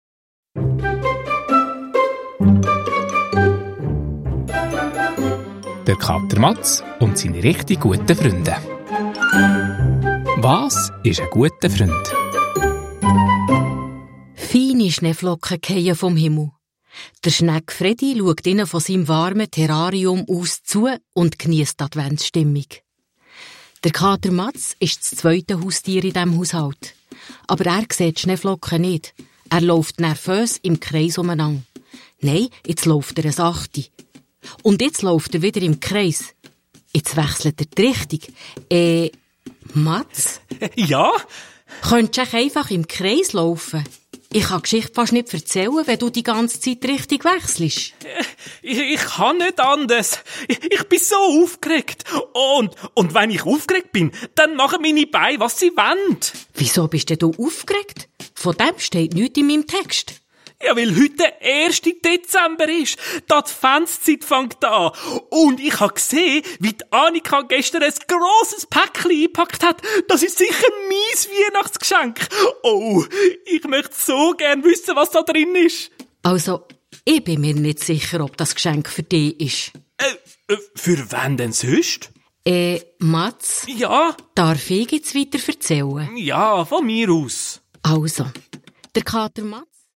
Das Adventshörspiel in 24 Tagesportionen und mit Türchenkalender bringt einerseits humorvolle Weihnachtsstimmung ins Kinderzimmer, aber auch Gedankenanstösse und Hoffnung zum Thema ein Freund sein.
Hörspiel-Album (ohne Kalender)